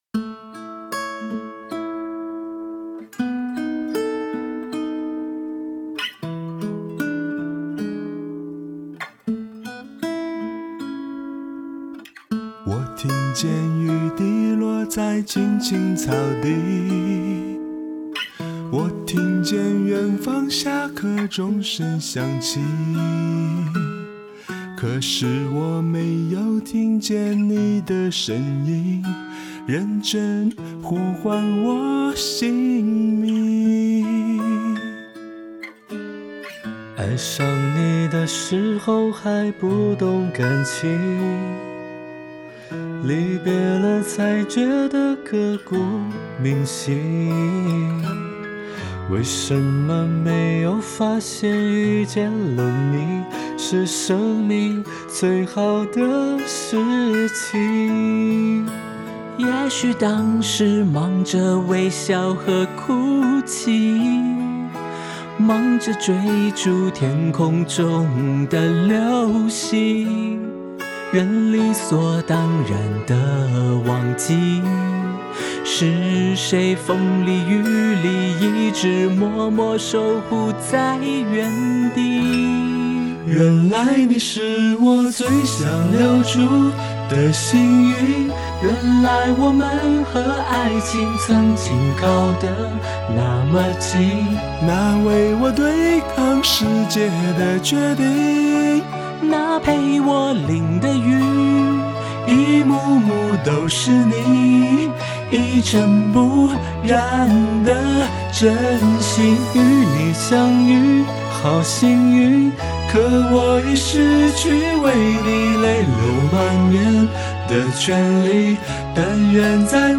标签: 翻唱